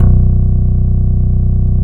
CLEAN BASS-L.wav